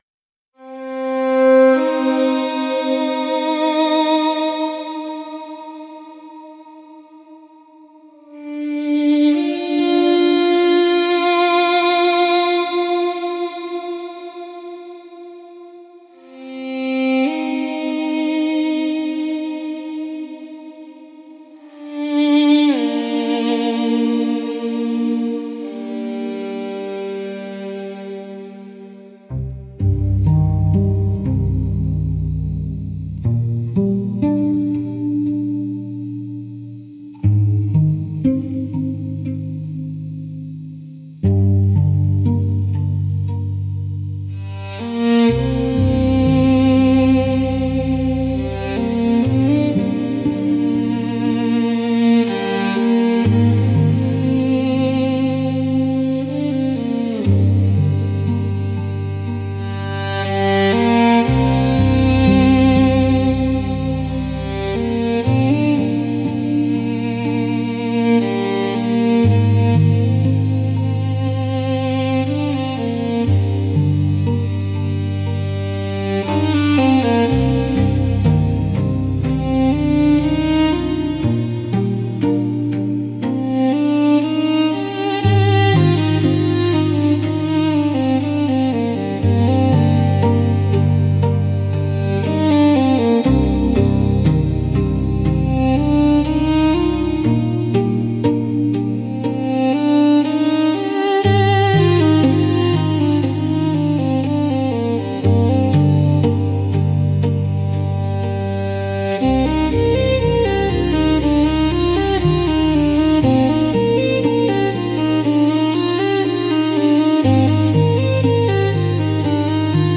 Relaxing Instrumental Music